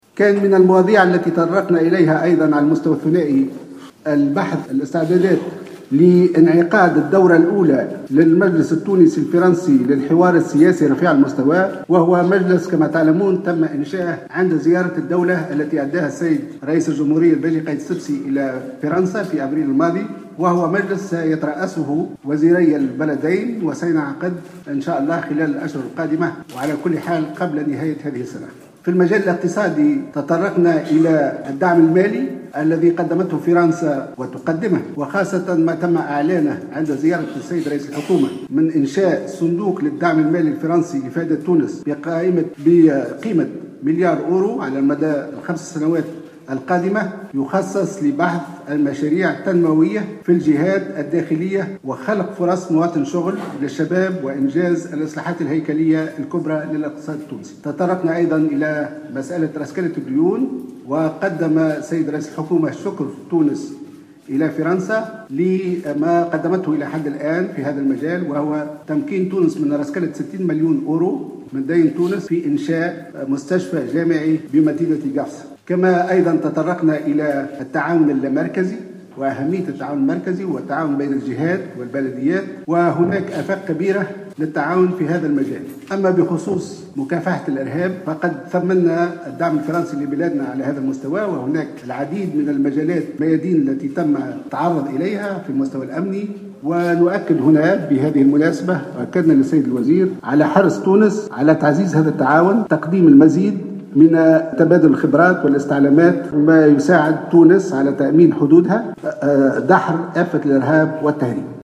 أعلن وزير الخارجية خميس الجهيناوي خلال ندوة صحفية عقدها اليوم الجمعة 18 مارس 2016 بعد استقباله لنظيره الفرنسي جون مارك إيرو عن قيمة ومجال الدعم المالي الذي قدمته فرنسا لتونس.